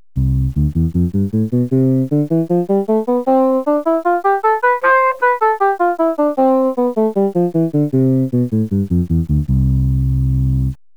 A 3-octave scale of notes that are simple transpositions of the instrument's highest tone is played
This is how the bassoon would sound if all tones had the same relative spectrum.